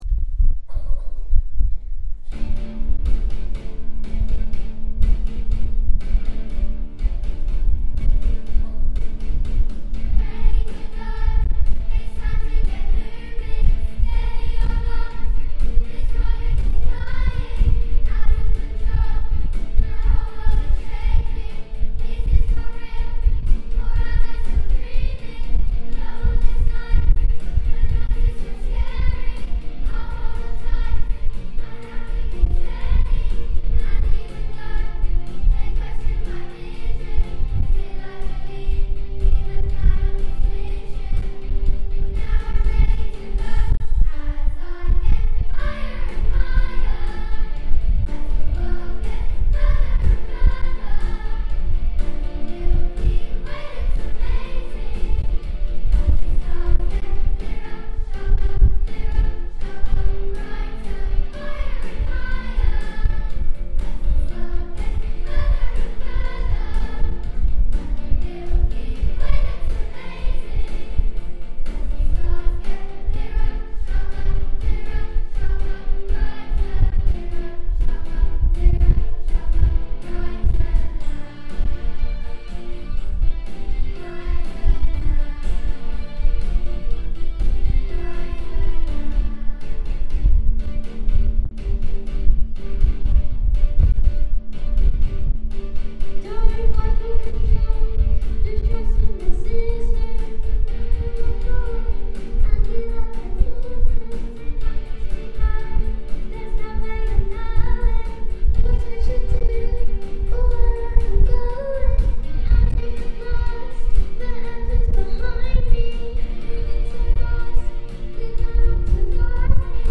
Higher and Higher - Year 4, 5, 6 Choir